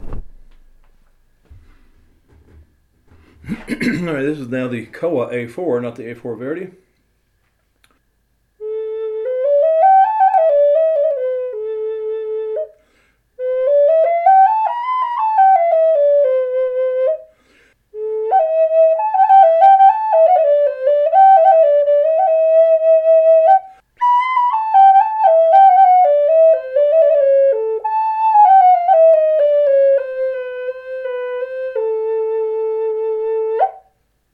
A4 Koa with a Pepperwood sweeptail bird. ADOPTED!